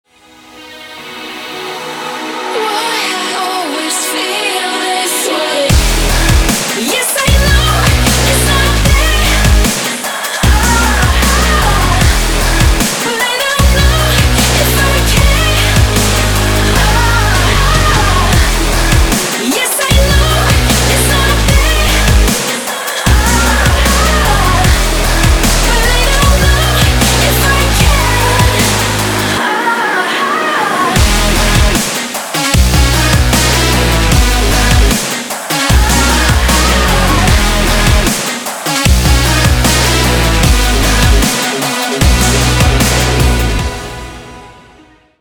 Дабстеп рингтоны